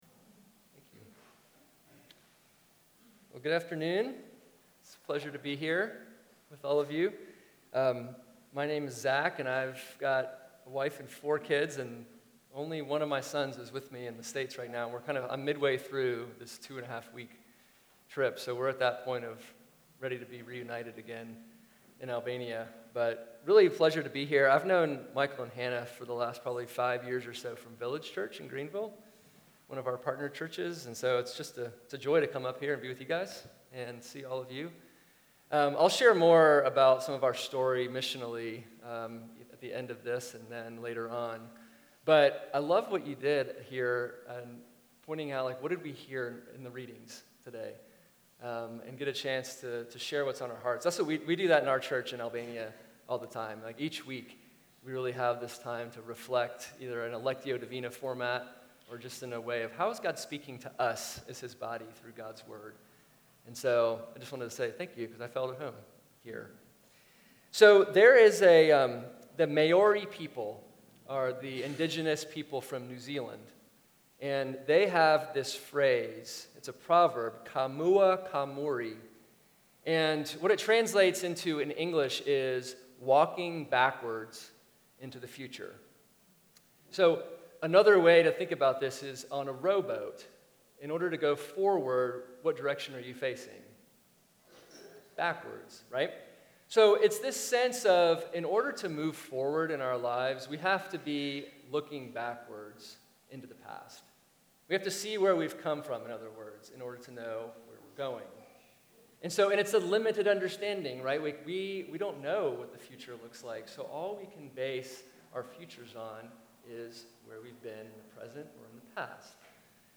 from the Second Sunday of Lent